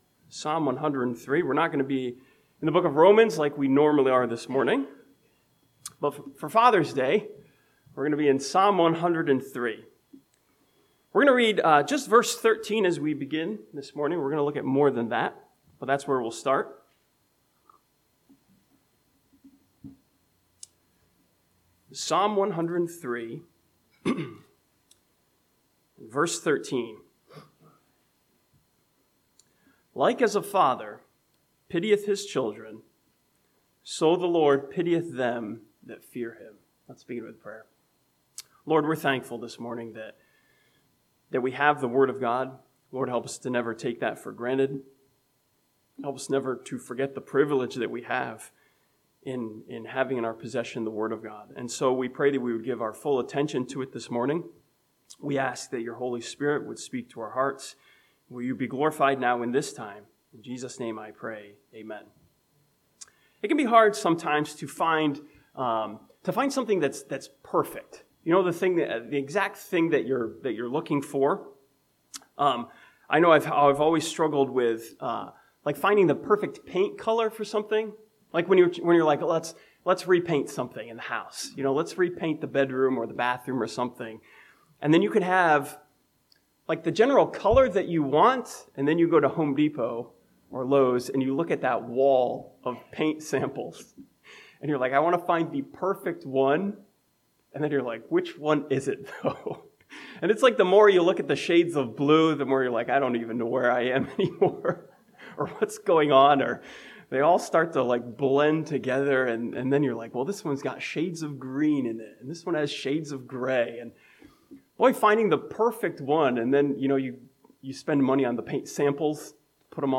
This Father's Day sermon from Psalm 103 takes a look at our heavenly Father as the perfect Father who understands, forgives, and cares for His children.